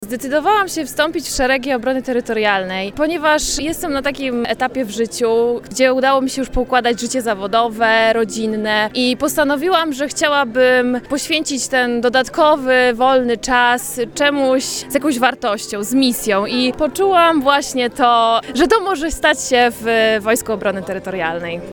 Pięćdziesięciu dziewięciu ochotników oficjalnie wstąpiło dziś w szeregi 3. Podkarpackiej Brygady Obrony Terytorialnej. To kobiety i mężczyźni, którzy na Placu majora Jana Gryczmana w Nowej Dębie przysięgali dziś na sztandar brygady.